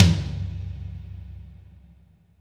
CM TOM  5.wav